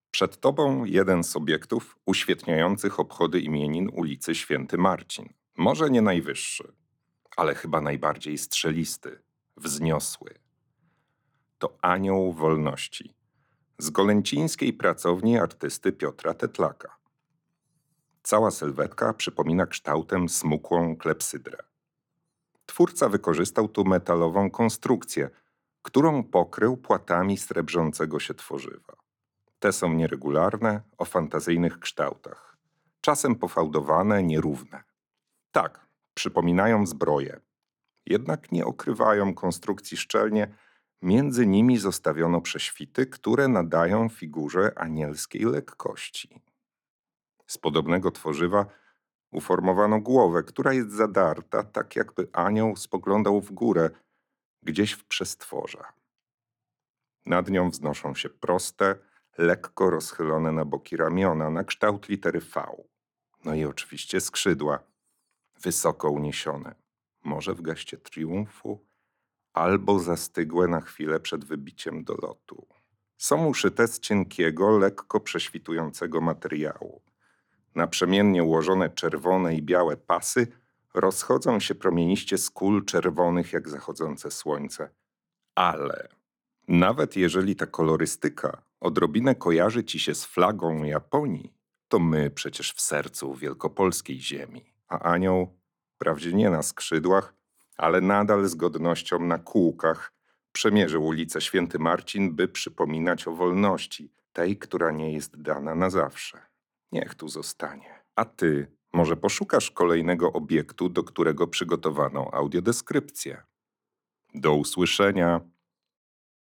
Anioł Wolności – audiodeskrypcja